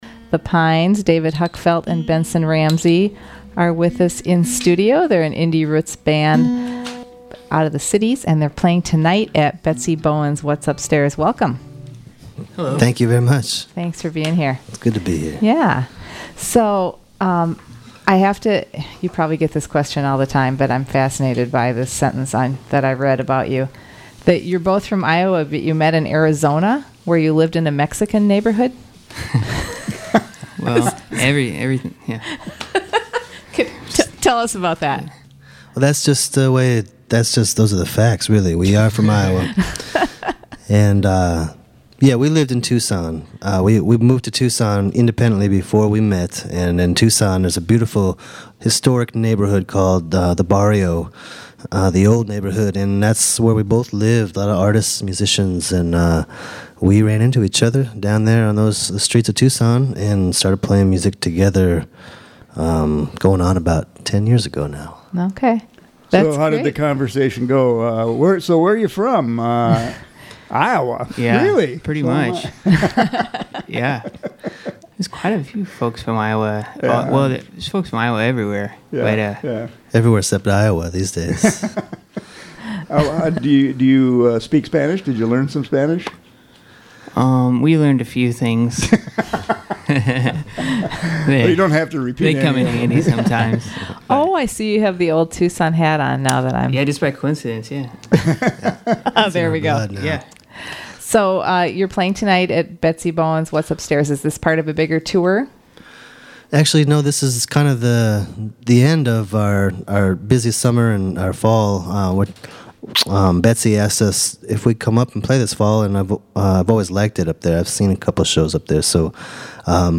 Listen for beautiful melodies and haunting lyrics.
Live Music Archive